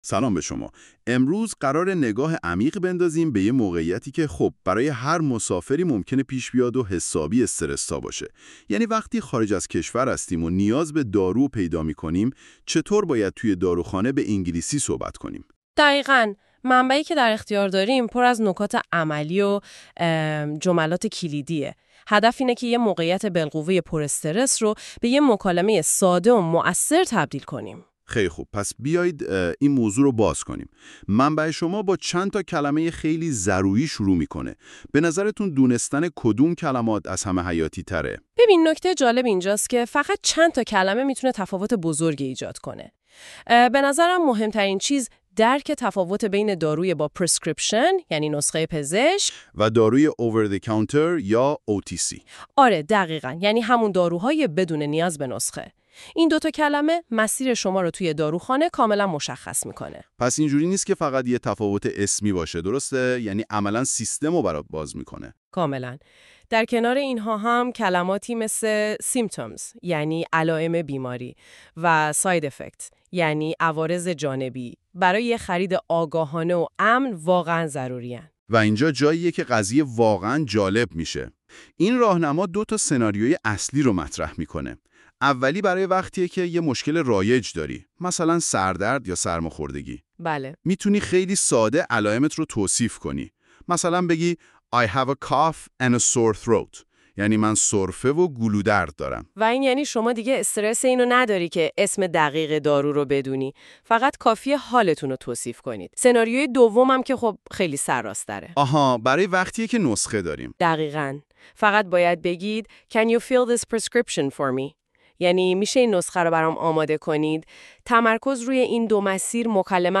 english-conversation-at-the-pharmacy.mp3